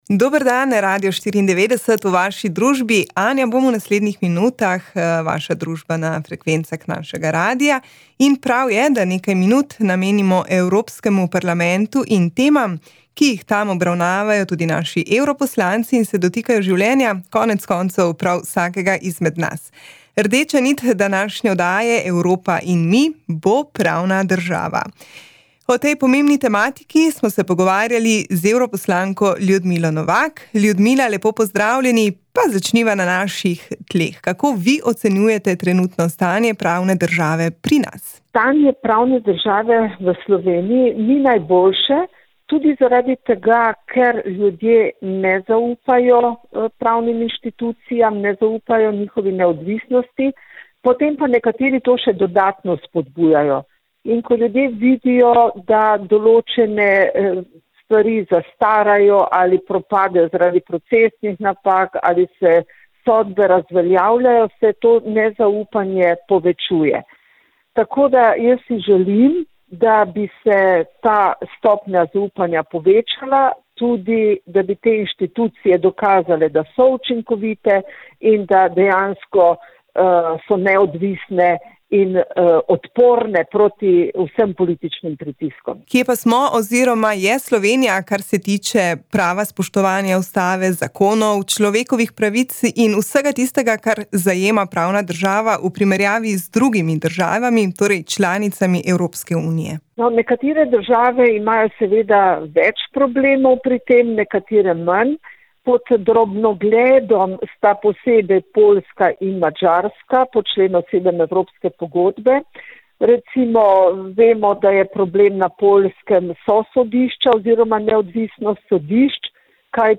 Z evropsko poslanko Ljudmilo Novak smo se pogovarjali o stanju pravne države pri nas, o mehanizmih, ki so po njenem mnenju potrebni za spodbujanje skupne kulture pravne države in preprečevanju težav na področju pravne države in nenazadnje učinkovitem odzivanju.